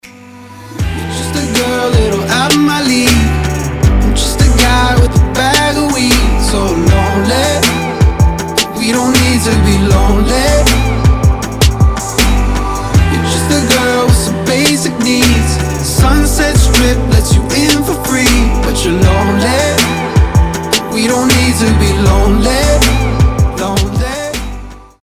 • Качество: 320, Stereo
мужской голос
спокойные
Midtempo